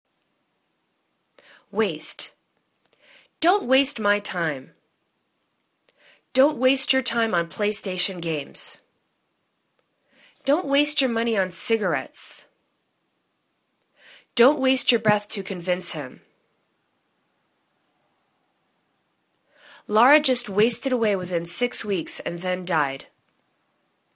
waste     /'wa:st/    v